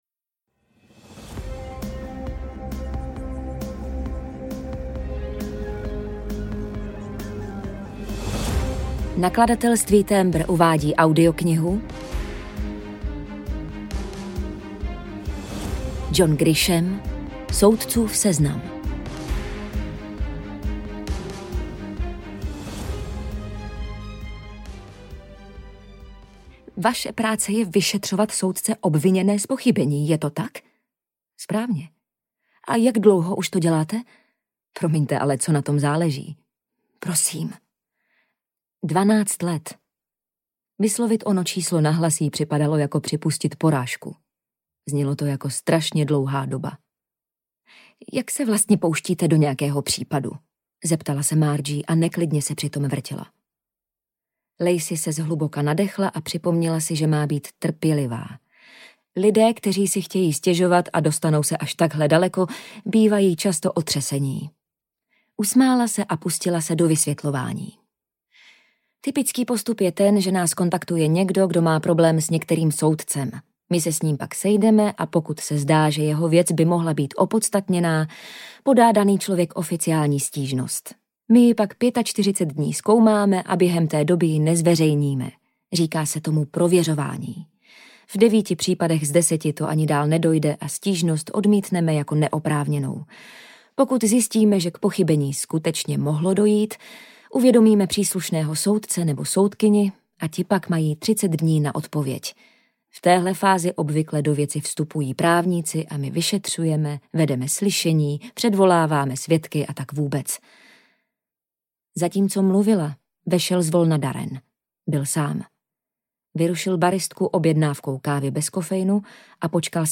Soudcův seznam audiokniha
Ukázka z knihy
soudcuv-seznam-audiokniha